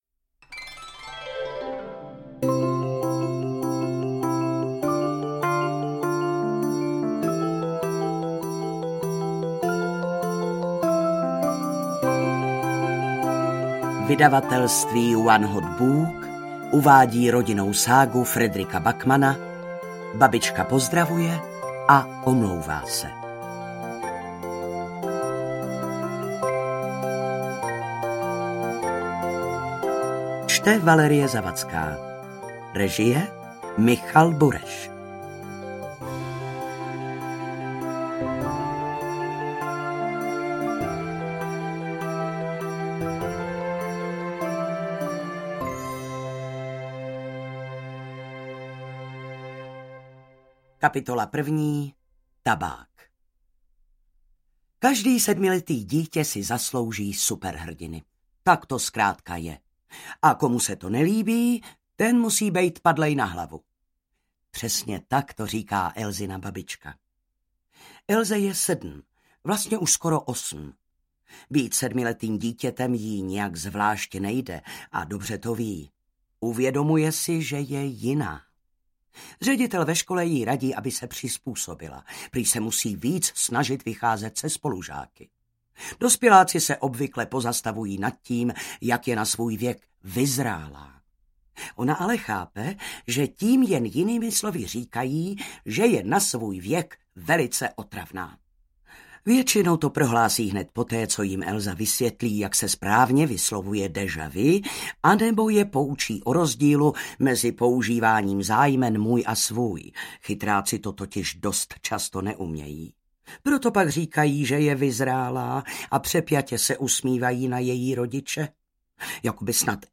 Ukázka z knihy
• InterpretValérie Zawadská